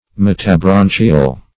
Search Result for " metabranchial" : The Collaborative International Dictionary of English v.0.48: Metabranchial \Met`a*bran"chi*al\, a. [Meta- + branchial.]